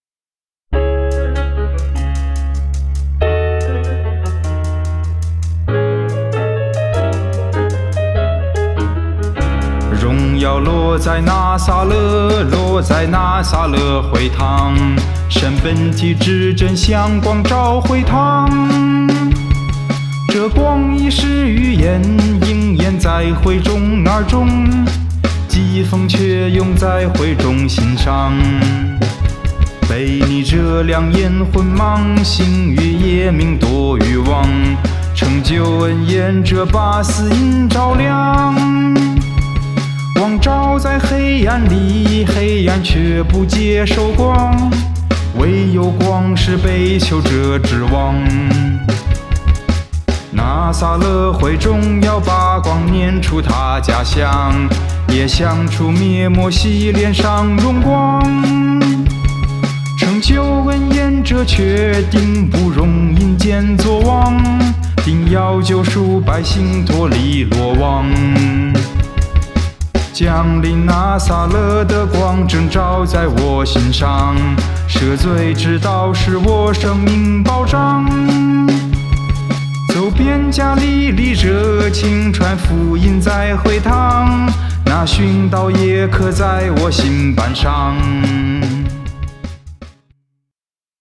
献唱/赞美新歌《拿撒勒会堂》